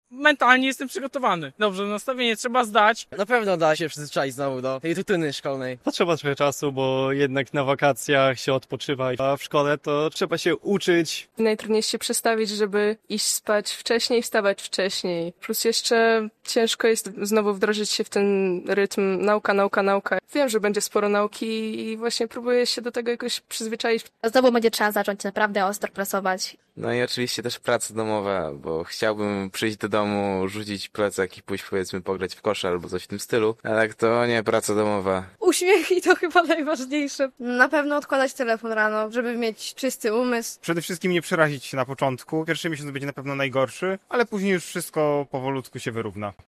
Uczniowie wracają do szkoły - relacja